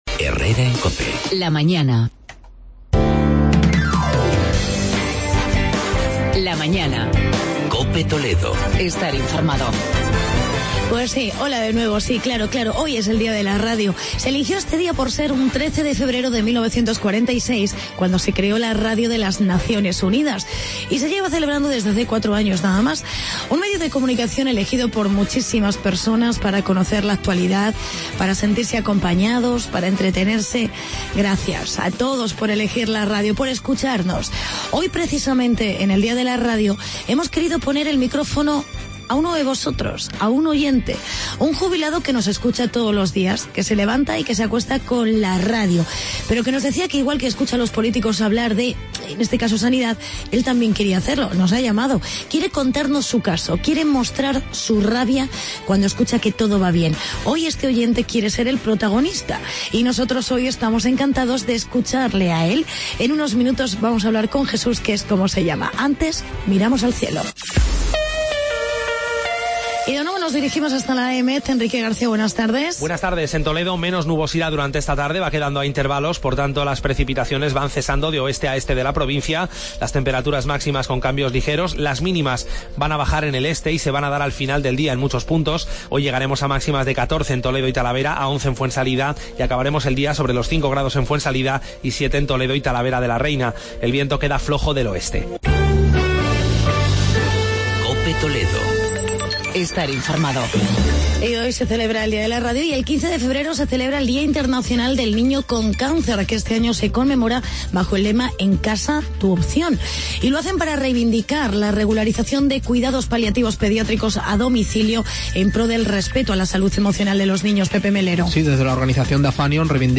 Actualidad y entrevista con un oyente hablando de Sanidad.